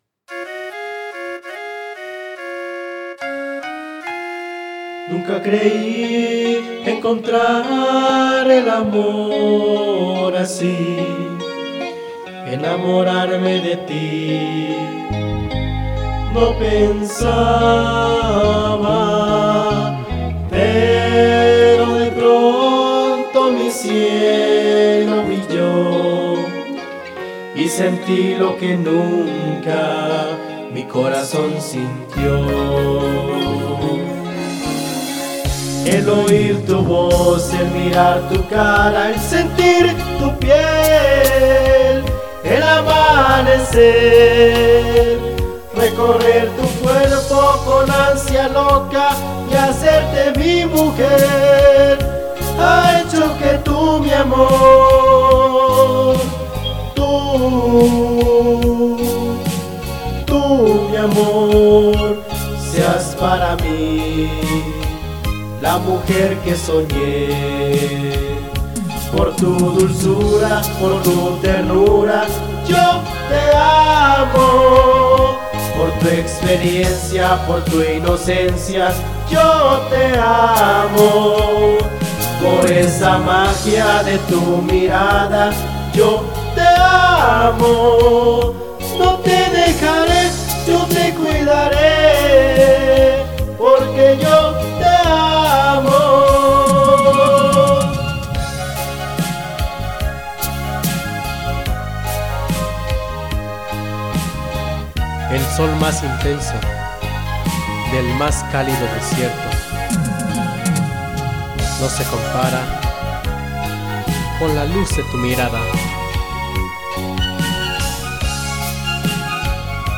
ahora a qui les comparto un cover cantado por mi.
esta edición lo hice con audaciti.
tengo algunos efectos.